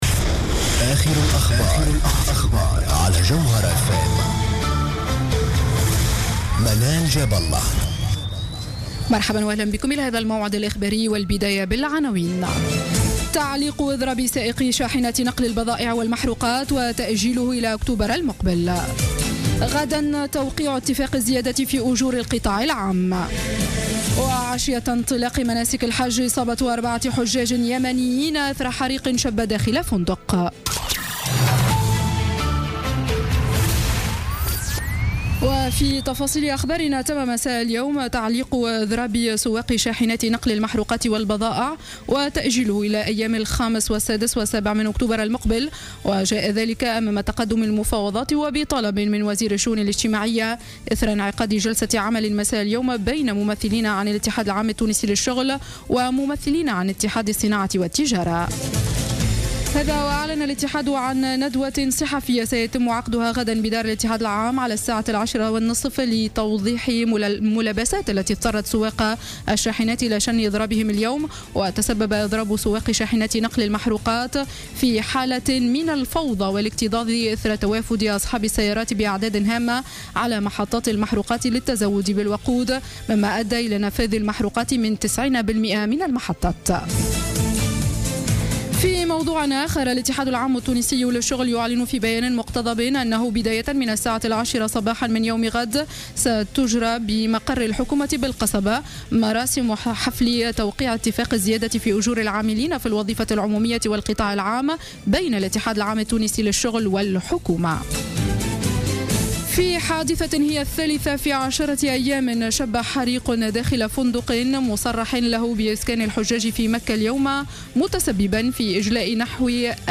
نشرة أخبار السابعة مساء ليوم الاثنين 21 سبتمبر 2015